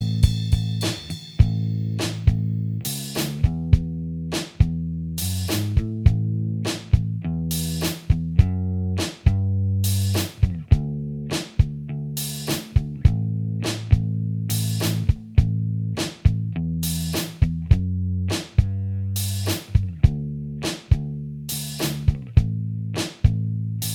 With Harmonies Pop (1960s) 2:21 Buy £1.50